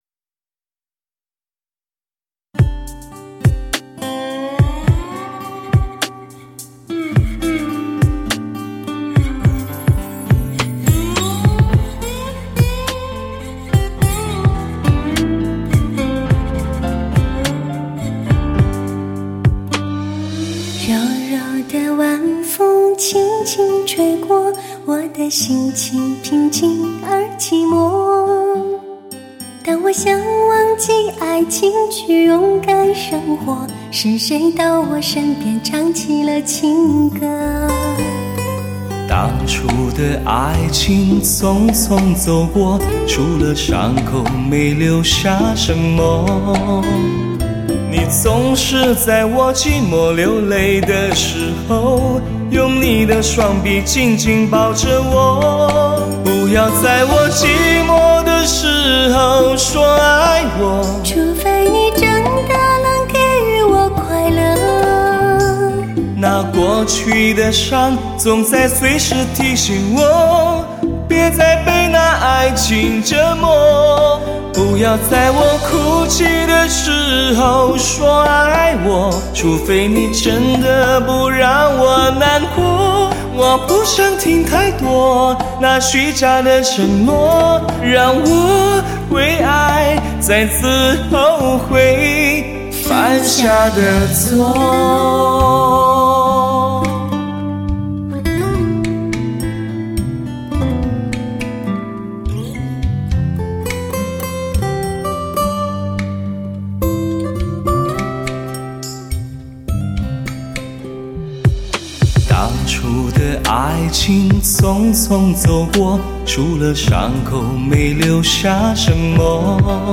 精选十六首脍炙人口的流行典范金曲，配以16种最先进的录音技术及16类配器手法，打造08年度开年天碟！